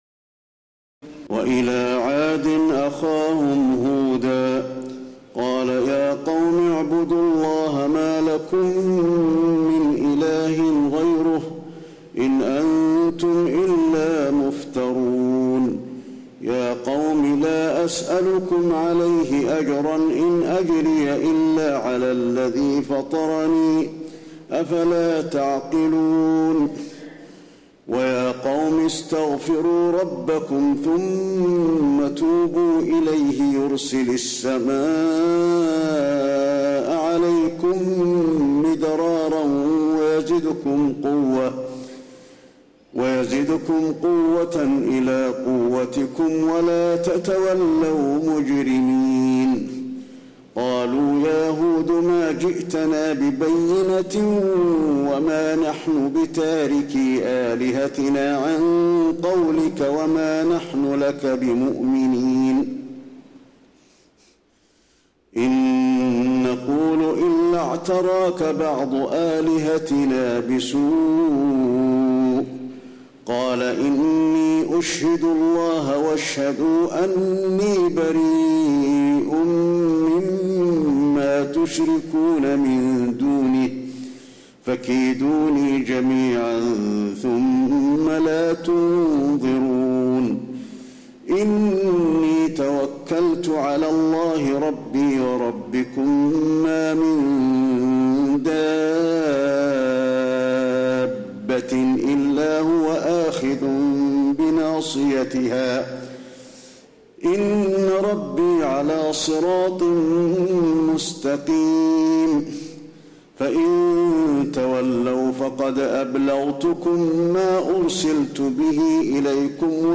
تراويح الليلة الثانية عشر رمضان 1432هـ من سورتي هود (50-123) و يوسف (1-42) Taraweeh 12 st night Ramadan 1432H from Surah Hud and Yusuf > تراويح الحرم النبوي عام 1432 🕌 > التراويح - تلاوات الحرمين